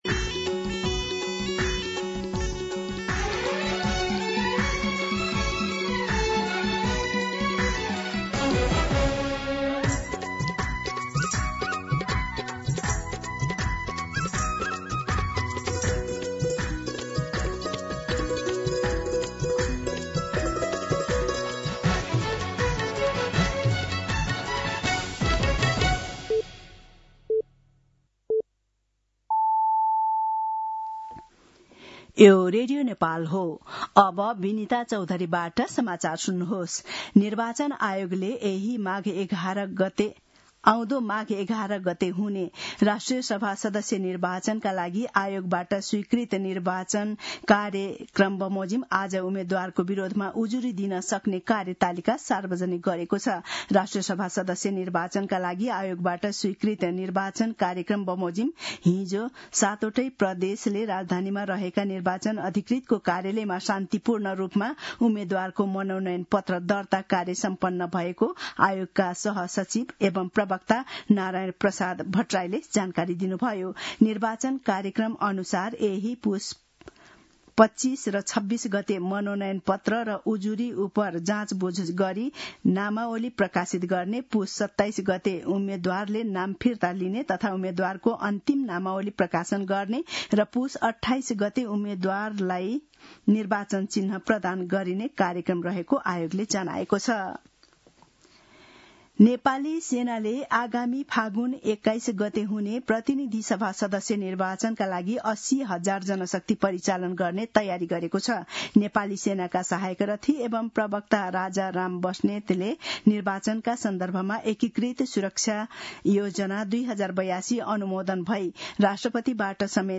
दिउँसो १ बजेको नेपाली समाचार : २४ पुष , २०८२
1pm-News-09-24.mp3